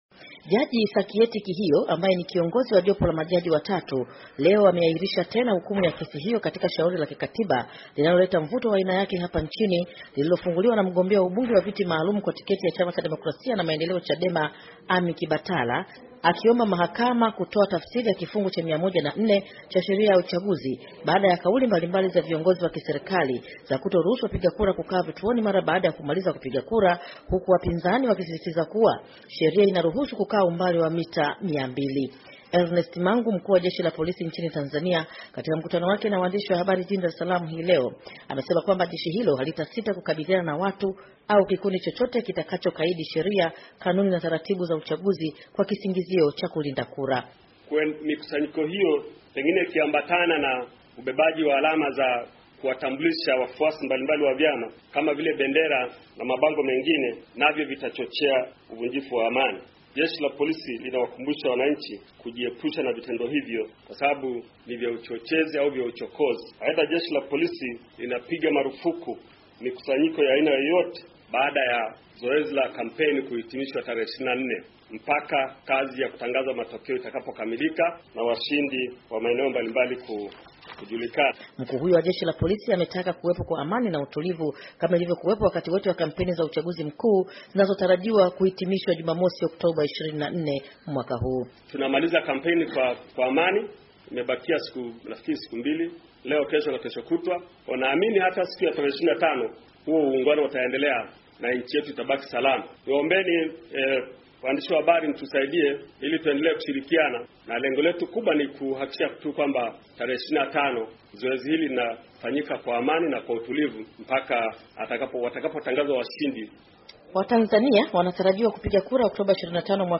Ripoti